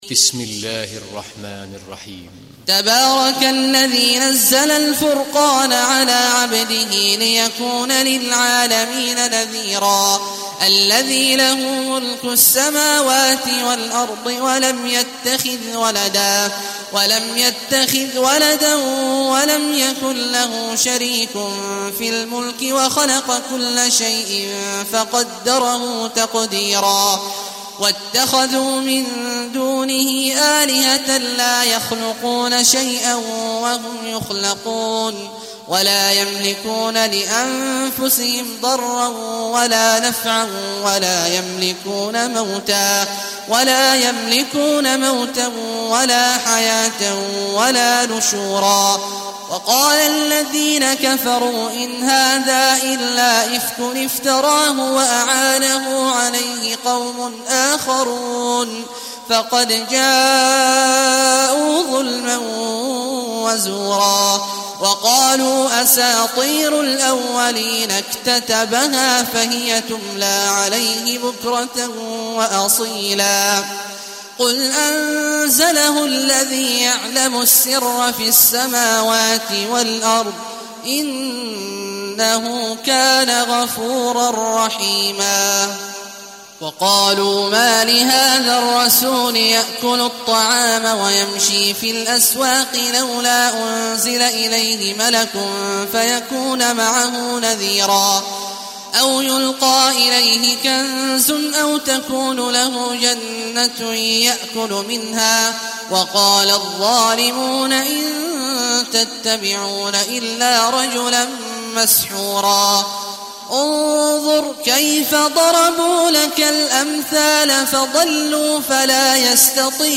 دانلود سوره الفرقان mp3 عبد الله عواد الجهني (روایت حفص)